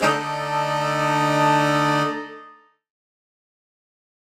UC_HornSwellAlt_Dmin9.wav